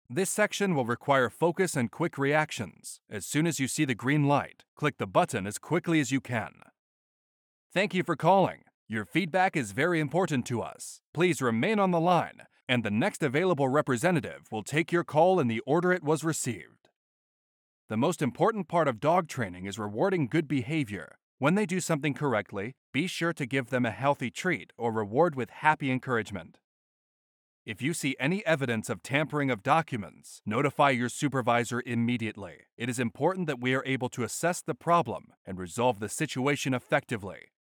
说明书音频